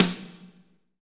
Index of /90_sSampleCDs/Northstar - Drumscapes Roland/DRM_AC Lite Jazz/SNR_A_C Snares x